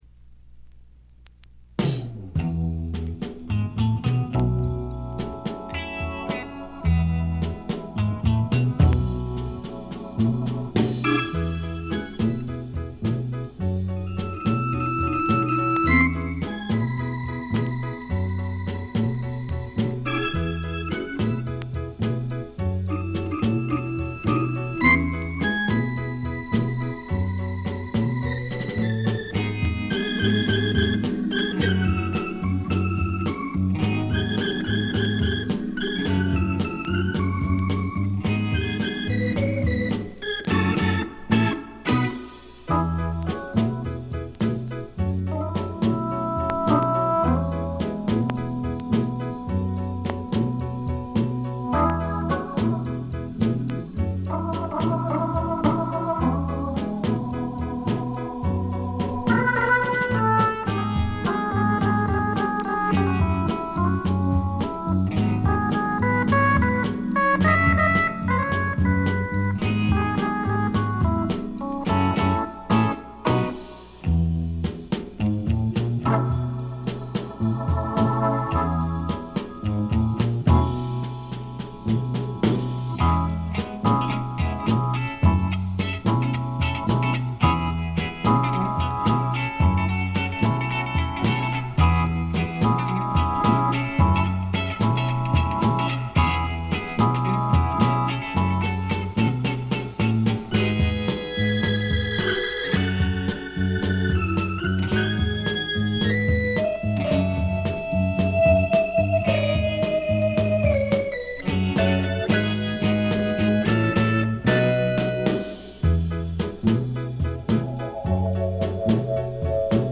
全体にチリプチ、プツ音多め大きめ。
試聴曲は現品からの取り込み音源です。